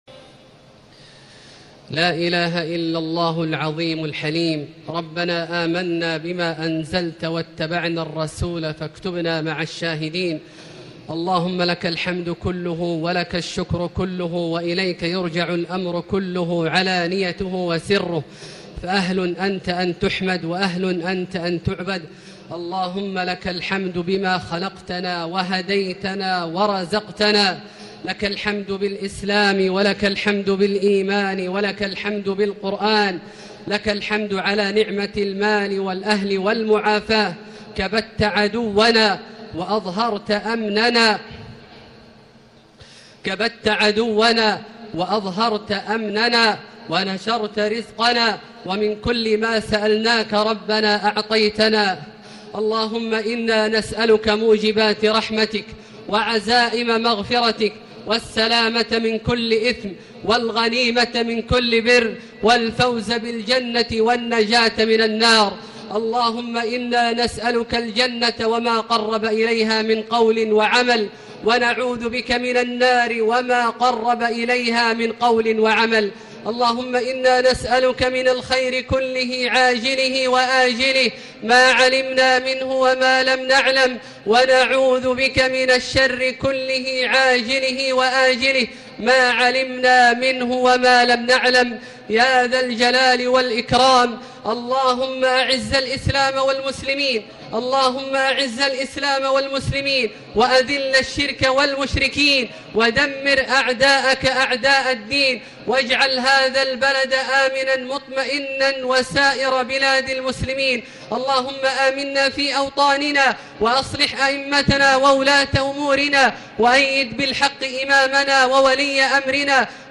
الدعاء
المكان: المسجد النبوي الشيخ: فضيلة الشيخ عبدالله الجهني فضيلة الشيخ عبدالله الجهني الدعاء